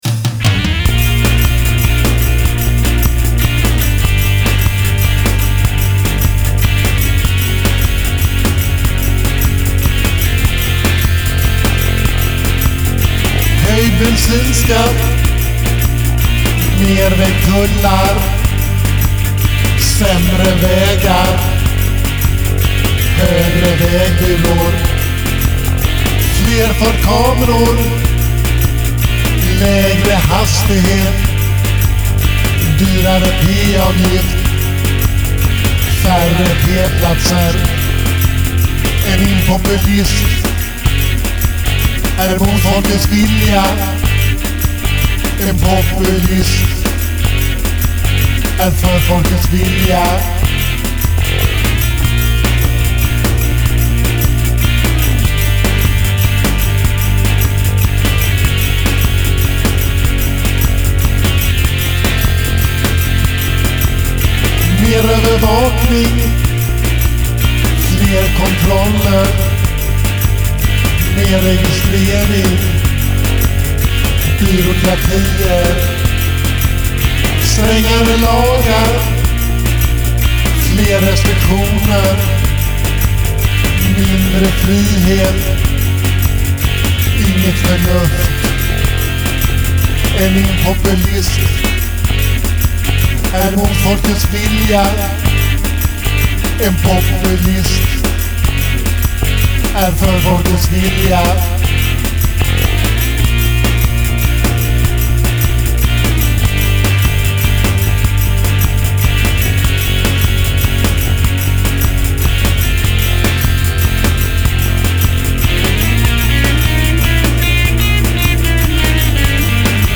Bb Gm F# F7
F# Gm F# E7